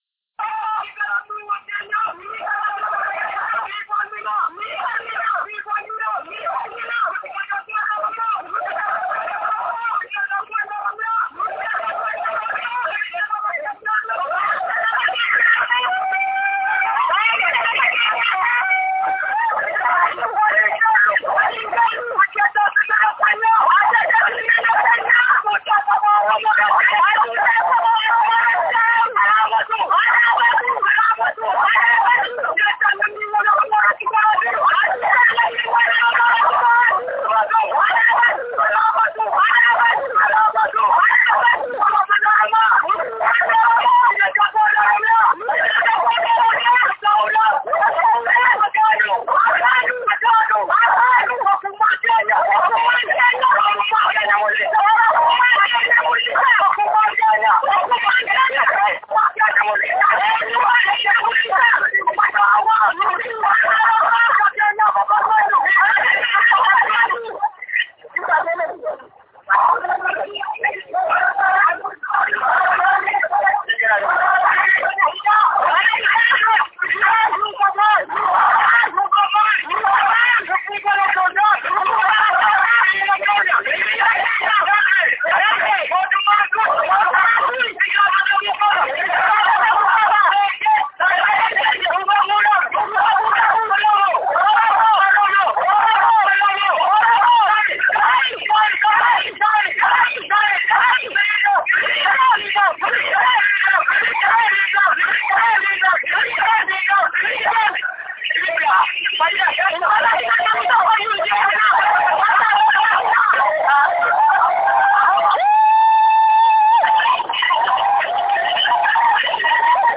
diddaa-buraayy-keessaa-fi-dhaadannoo.wav